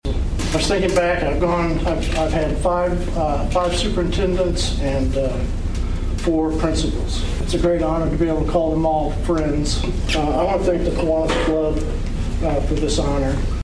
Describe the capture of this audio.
This week’s Carmi Kiwanis Club meeting was highlighted by the announcement of the club’s Educator of the year and Support Staff of the year awards.